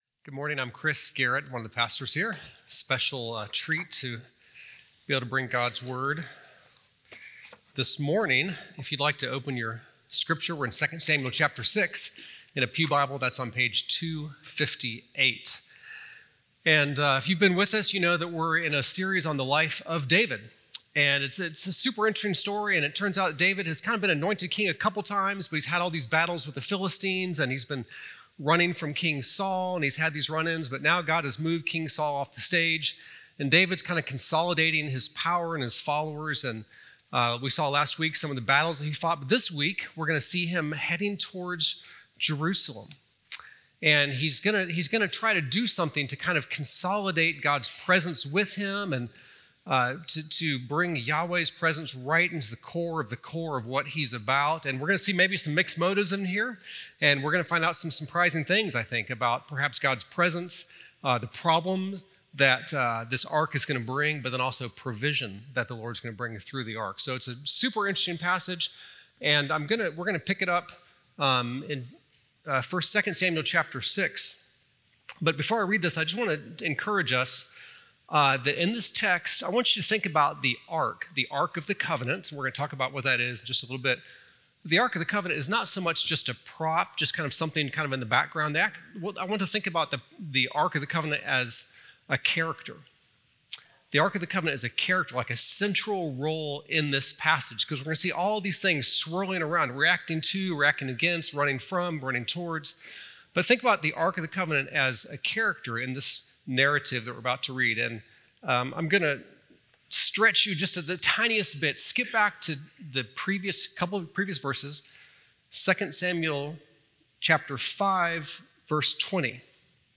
View this week’s sermon discussion questions .